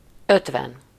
Ääntäminen
Ääntäminen France (Paris): IPA: [sɛ̃.kɒ̃t] Tuntematon aksentti: IPA: /sɛ̃.kɑ̃t/ Haettu sana löytyi näillä lähdekielillä: ranska Käännös Ääninäyte Adjektiivit 1. ötven Suku: m .